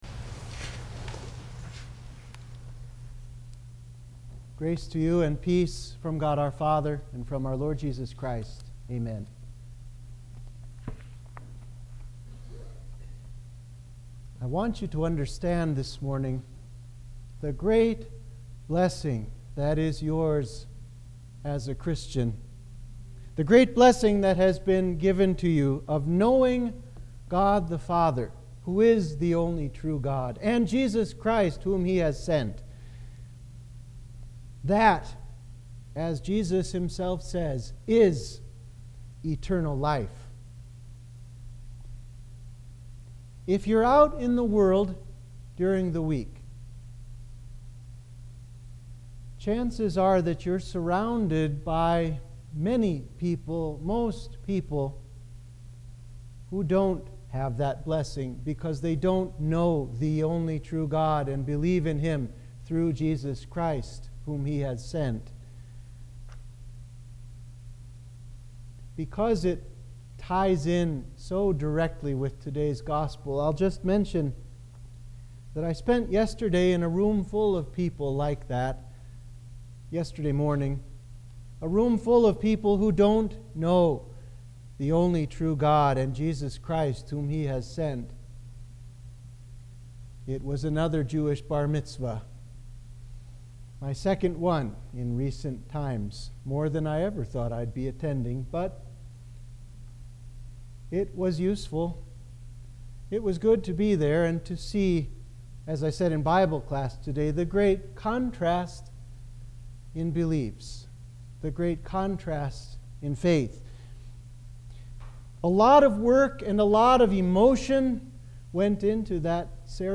Sermon for Trinity 11